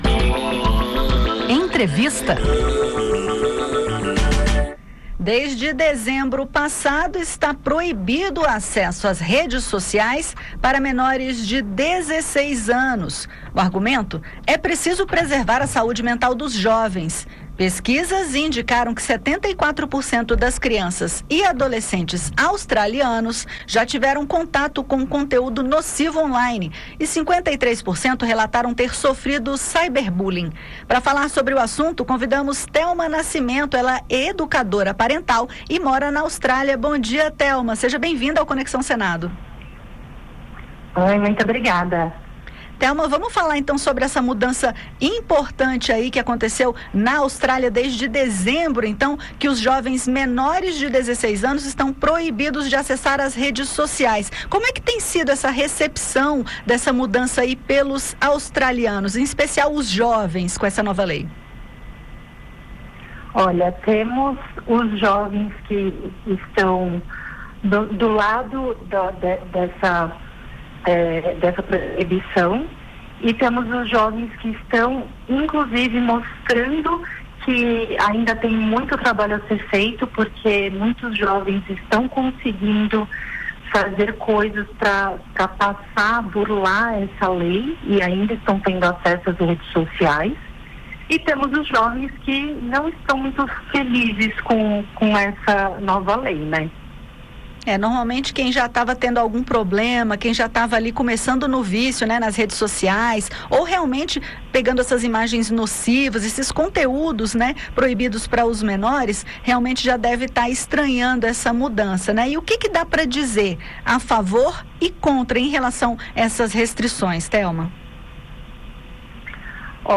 Em entrevista ao Conexão Senado